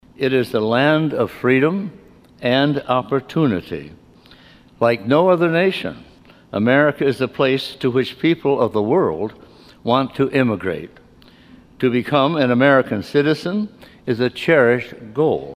In the State Senate Thursday morning, the 22nd annual memorial day service was held.